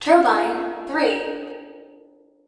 TURBINE3.mp3